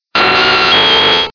Cries